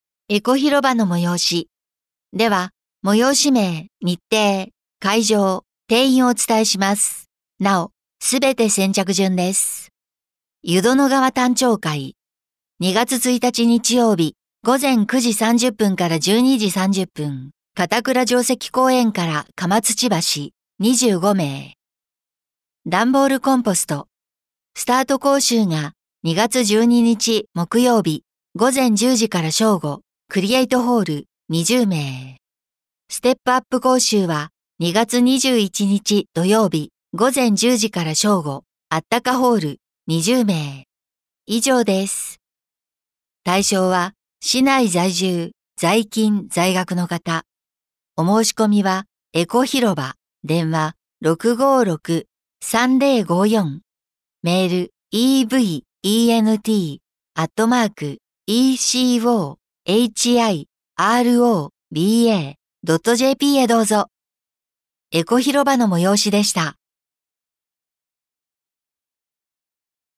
「声の広報」は、視覚障害がある方を対象に「広報はちおうじ」の記事を再編集し、音声にしたものです。